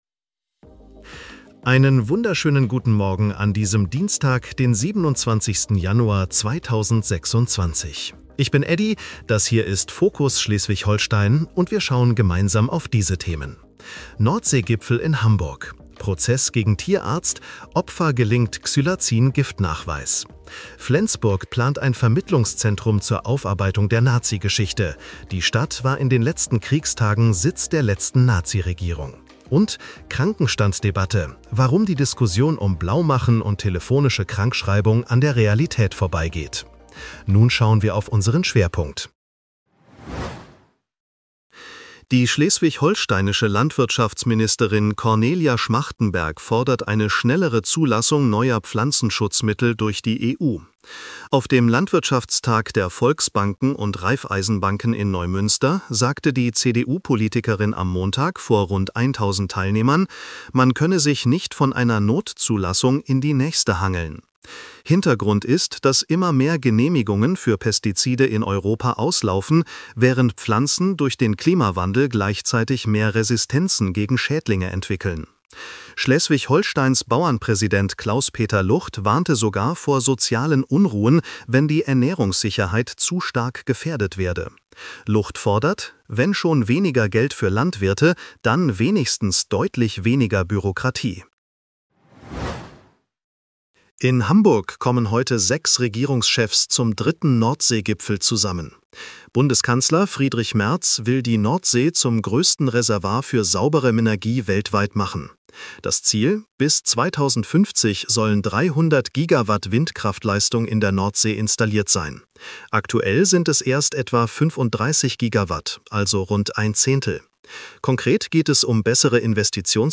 Nachrichten-Podcast bekommst Du ab 7:30 Uhr die wichtigsten Infos